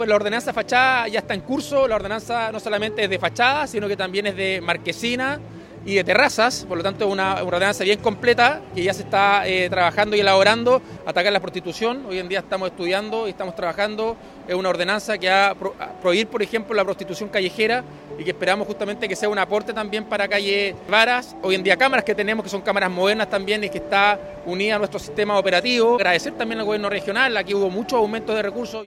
Para ello, consultado el alcalde, Rodrigo Wainraihgt, anunció dos ordenanzas enfocadas en la infraestructura, para fachadas, terrazas y marquesinas, así como en seguridad.
calle-varas-alcalde.mp3